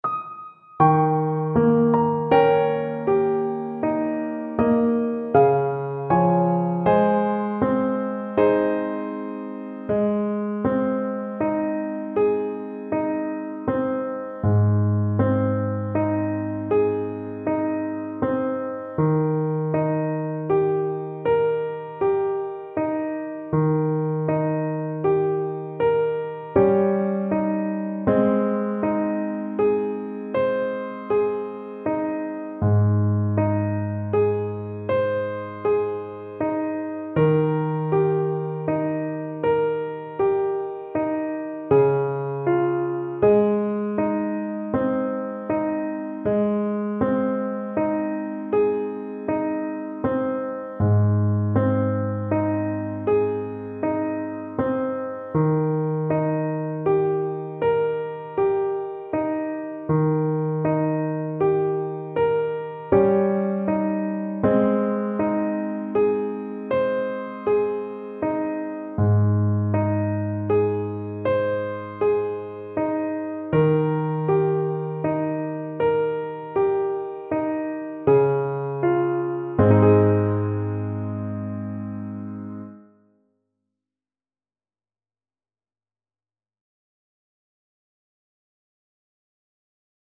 French Horn
Ab major (Sounding Pitch) Eb major (French Horn in F) (View more Ab major Music for French Horn )
Slow =c.60
3/4 (View more 3/4 Music)
Traditional (View more Traditional French Horn Music)
avondale_HN_kar3.mp3